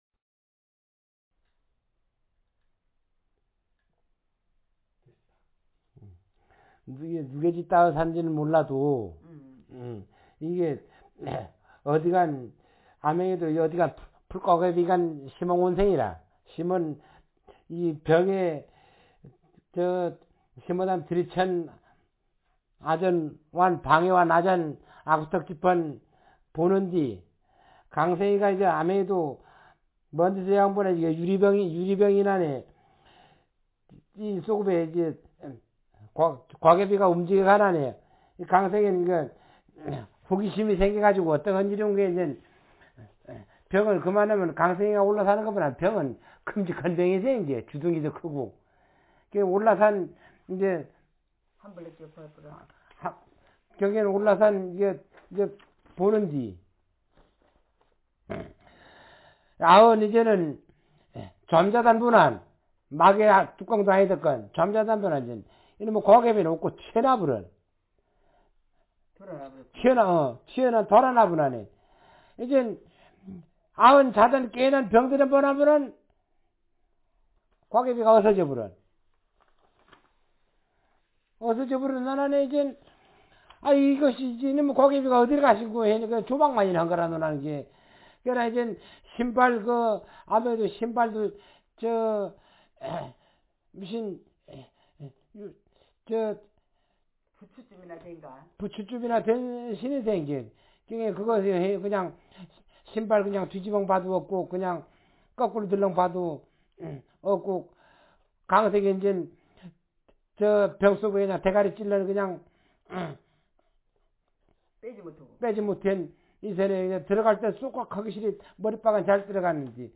Speaker sexm
Text genrestimulus retelling